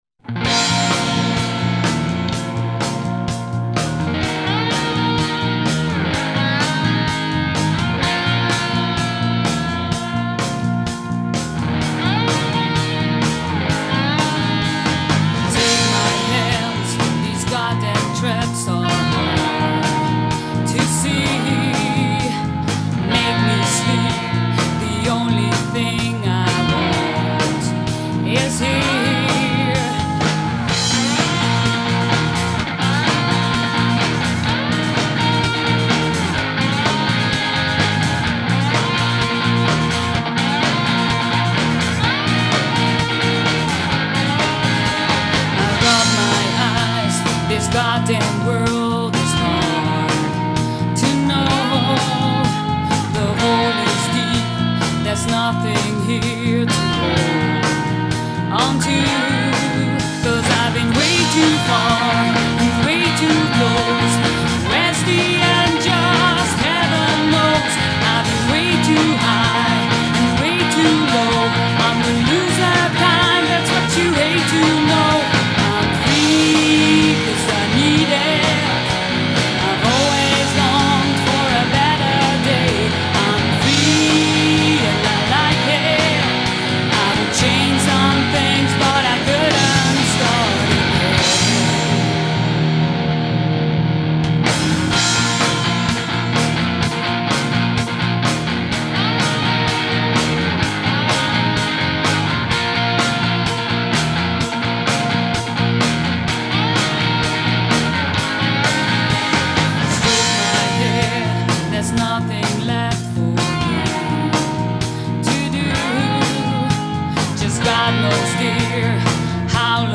Recorded at Rayment's Hill Studio